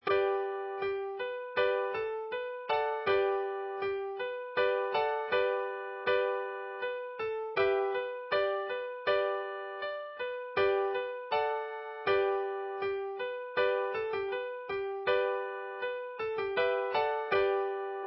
Inst=Instrumental (based on a MIDI file)